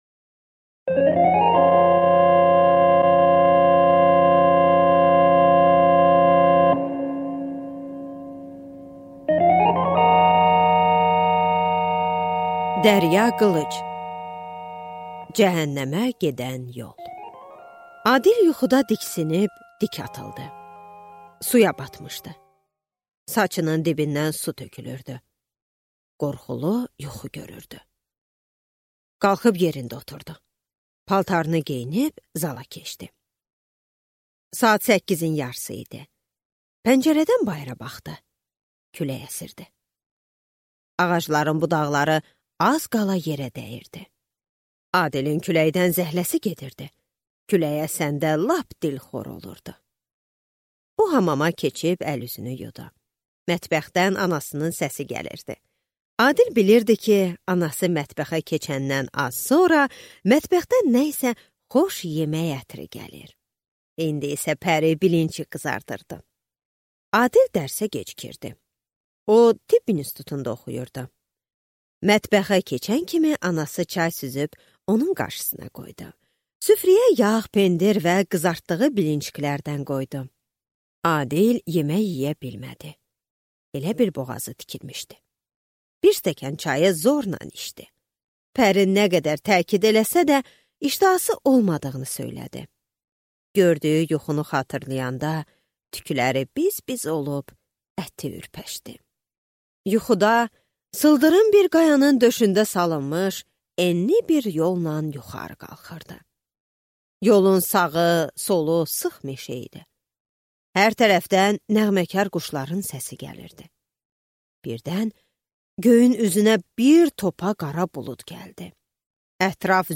Аудиокнига Cəhənnəmə gedən yol | Библиотека аудиокниг